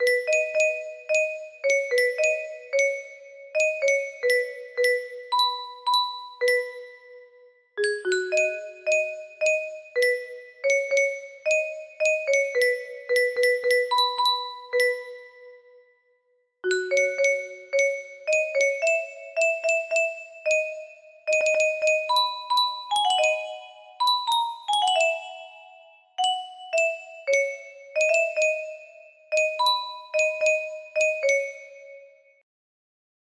(B major)